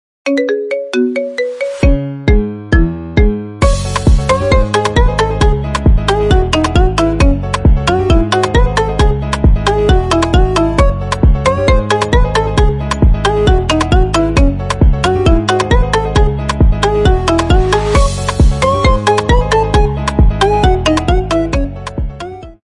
Dance
Marimba